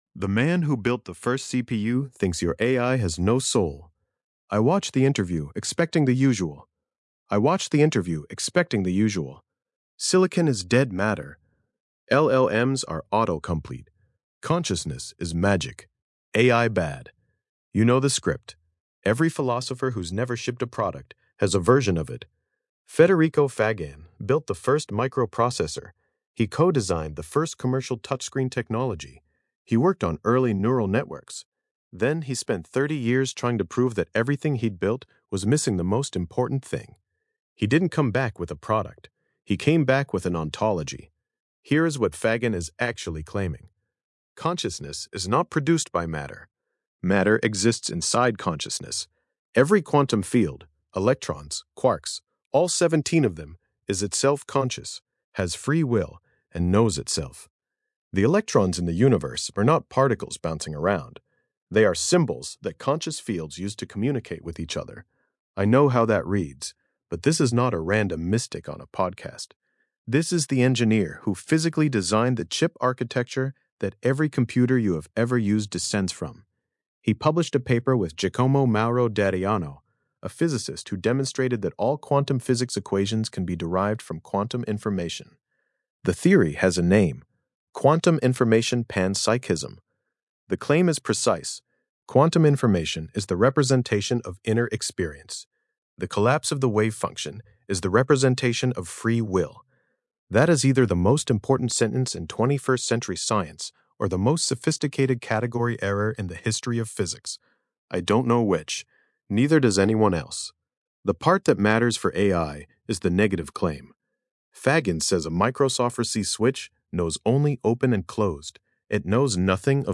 Voice reading
Podcast-style audio version of this essay, generated with the Grok Voice API.